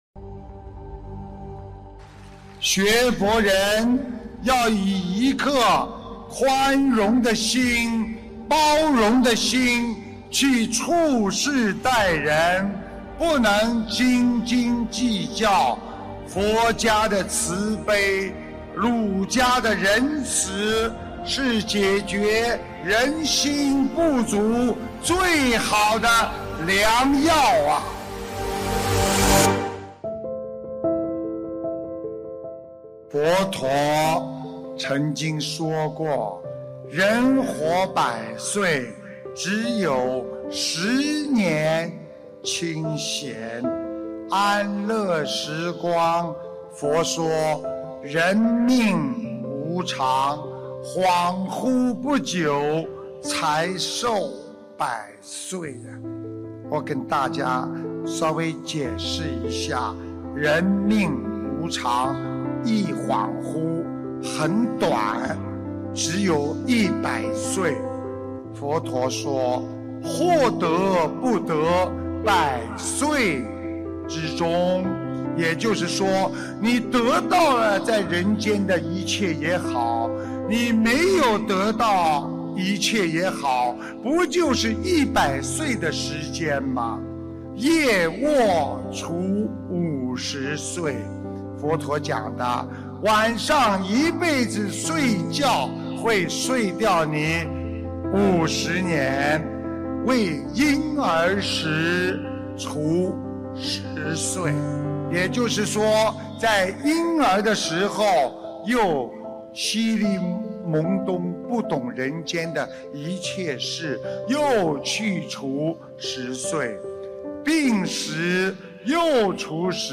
—— 2015年1月24日 马来西亚 槟城法会开示